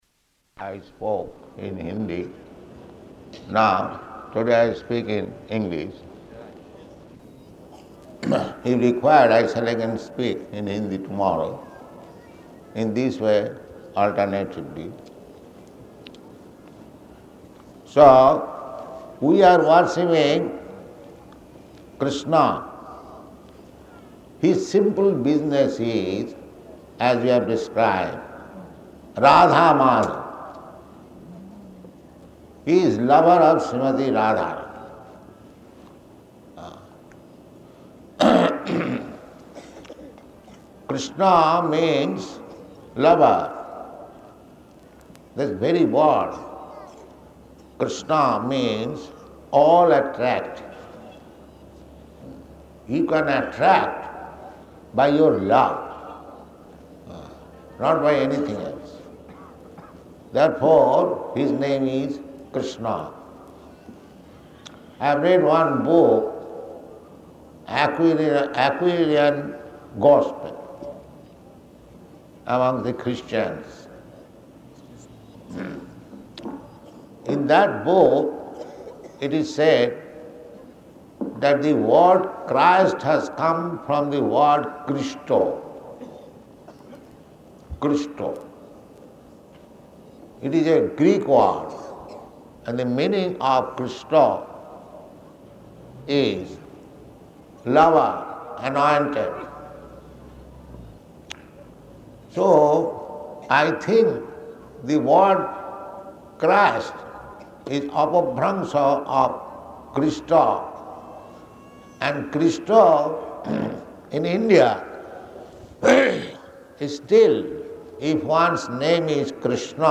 Lecture at Pandal
Lecture at Pandal --:-- --:-- Type: Lectures and Addresses Dated: November 16th 1973 Location: Delhi Audio file: 731116LE.DEL.mp3 Prabhupāda: ...I spoke in Hindi.